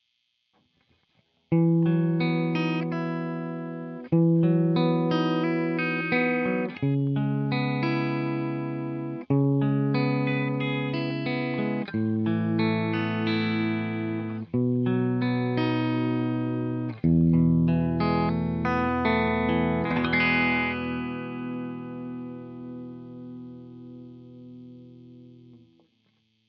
それで音はとてもクリアです、僕の機体だからかもしれませんが歪みよりクリーン系はホントに素敵な音がします
リア・フロント・センターの順で弾いてみました
何も音の装飾はしていない素の音です